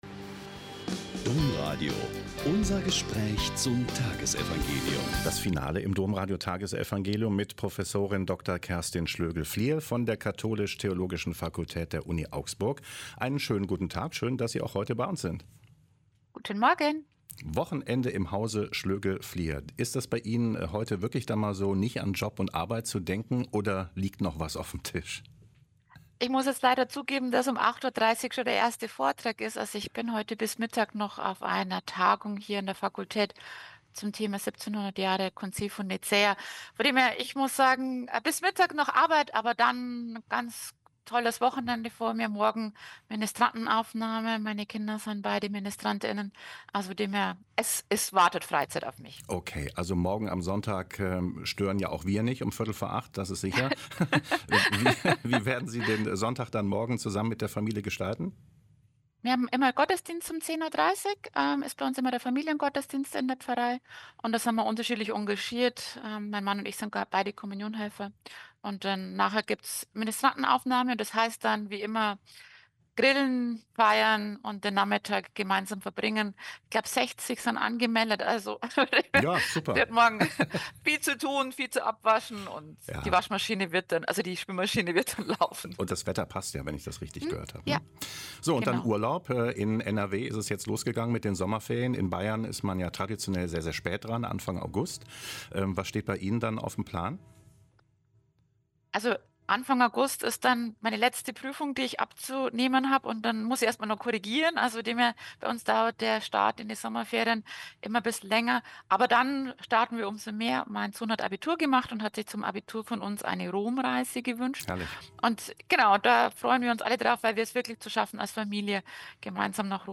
Mt 10,24-33 - Gespräch